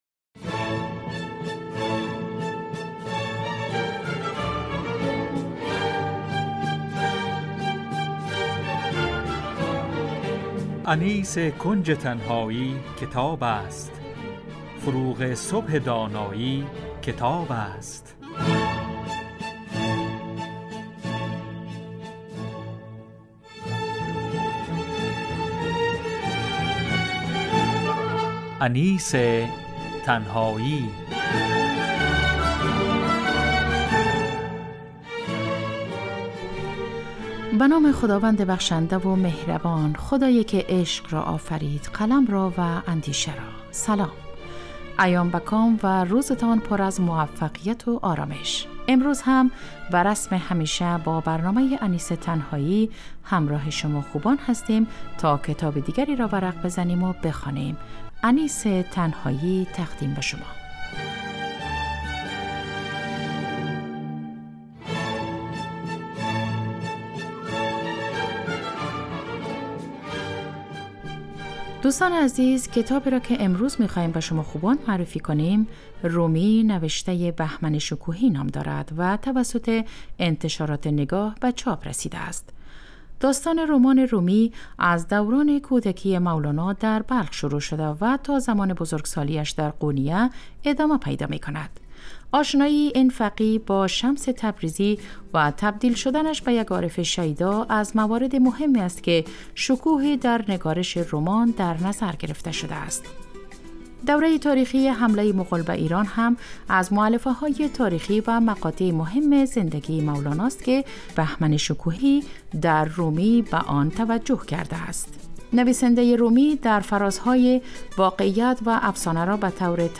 معرفی کتاب - کارشناس - کتاب صوتی